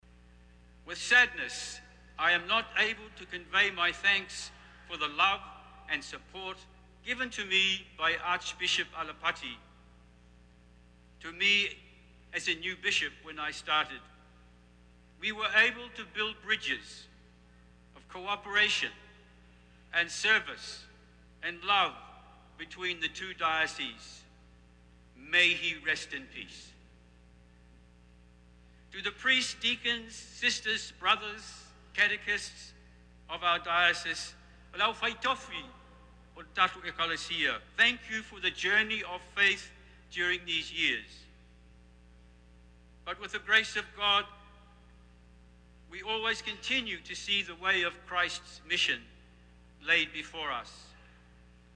In his thank you message at the end of the Mass, he gave special mention of the late Archbishop Alapati Mataeliga.
bishop-thank-you.mp3